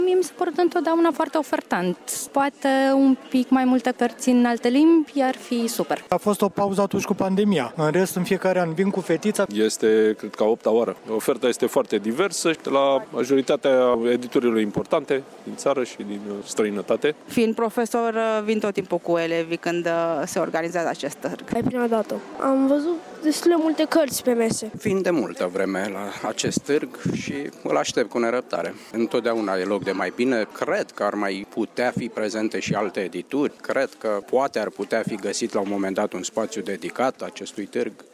Încă de dimineaţă, vizitatorii au venit la târg în număr mare.